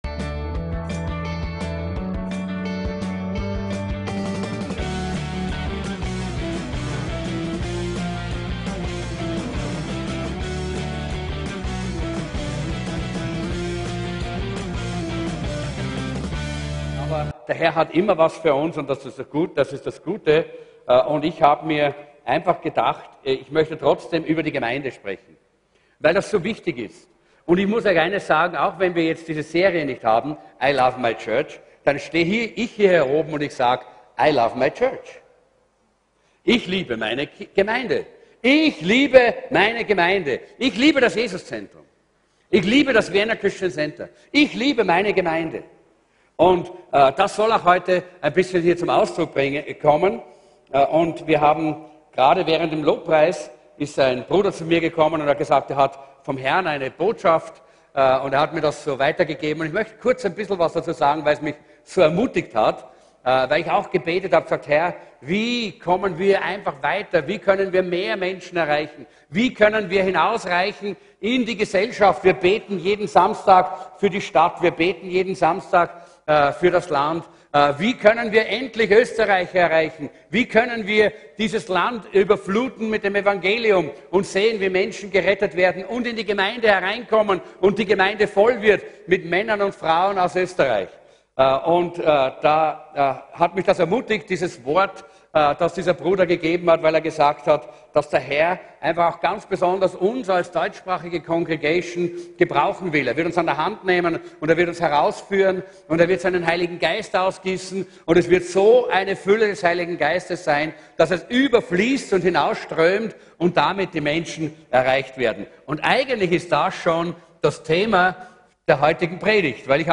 ECHTES CHRISTENTUM ~ VCC JesusZentrum Gottesdienste (audio) Podcast